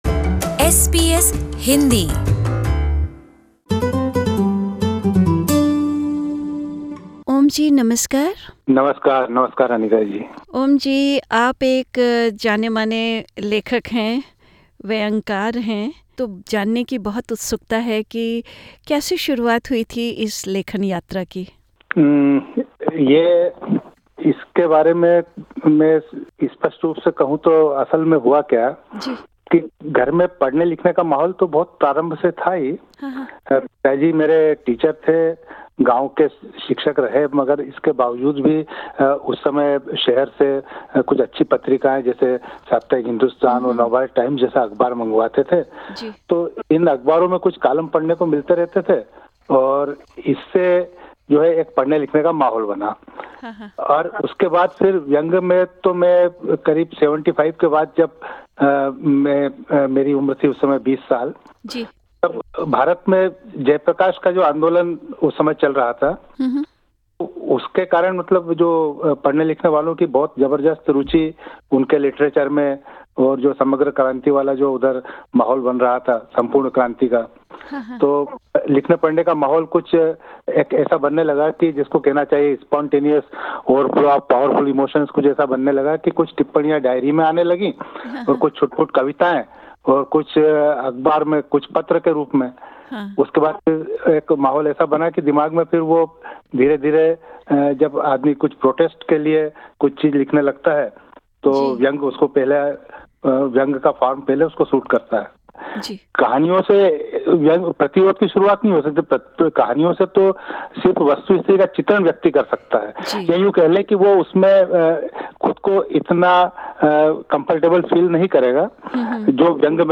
एक परिचय वार्ता कि आज के दौर में व्यंग्य कितना सार्थक है।